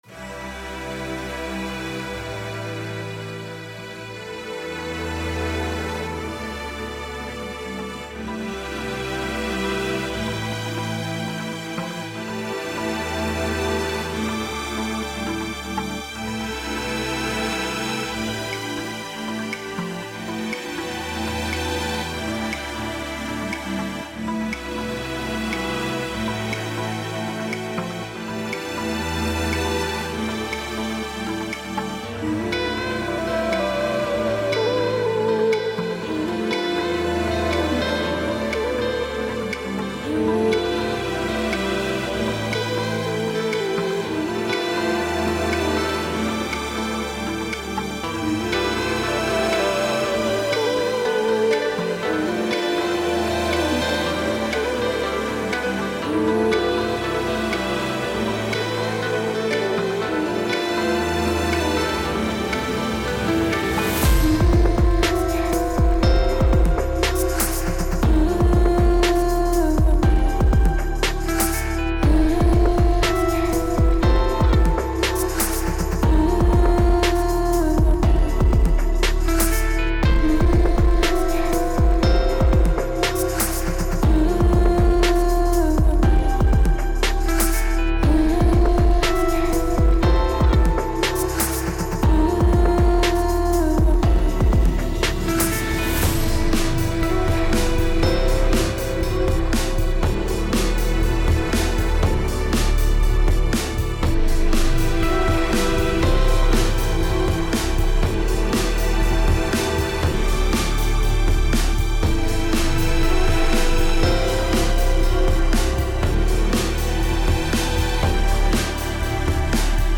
Tempo 120BPM (Allegro)
Genre OPERATIC FUTURE BASS
Type adlib-strumental
Mood CONFLICTING - CHILLED/ENERGETIC
PIANO KEY G MINOR (ADD9)
VOCAL KEYS F, C# MINOR
RENDER LOUDNESS -8 LUFS
INSTRUMENTS Bass - DEEP
Keys - Piano
Percussion - HANG DRUMS, Kick Drum, TOM, Hihats, CLICK
Plucks - Guitar
sTRINGS - eNSEMBLE